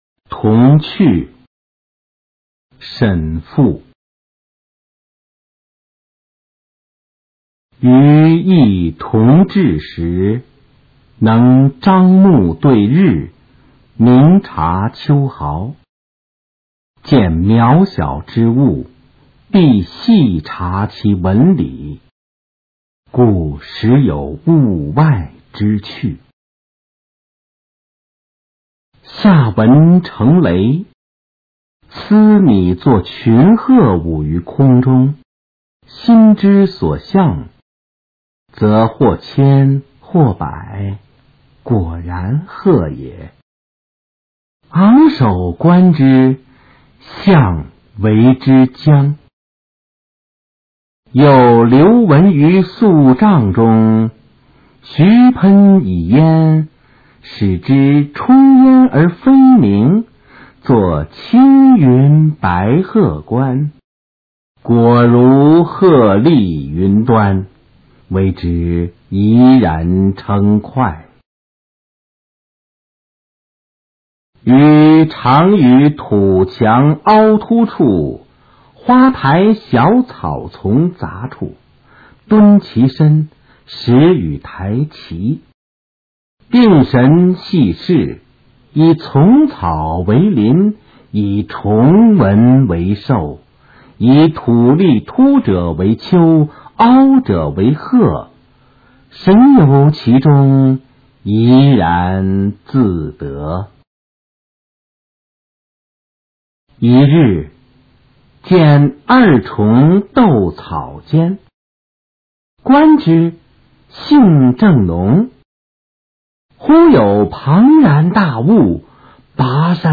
《幼时记趣》原文和译文（含朗读）